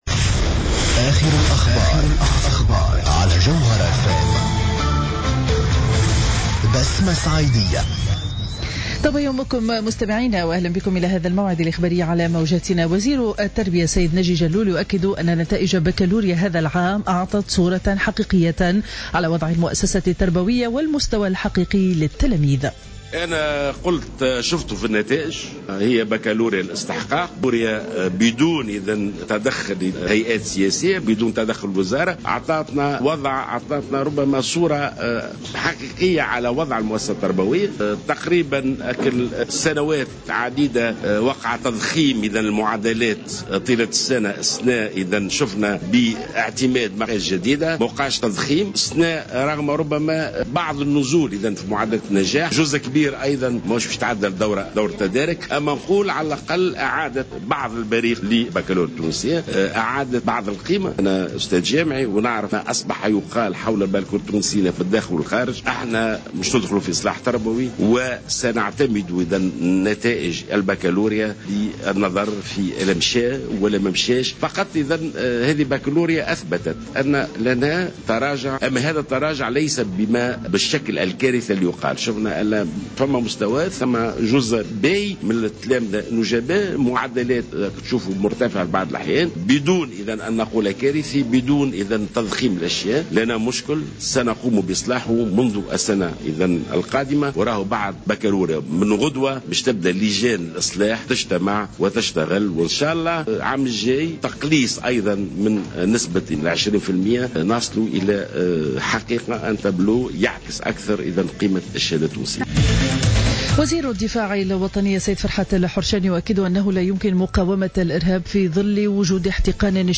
نشرة أخبار السابعة صباحا ليوم الأحد 21 جوان 2015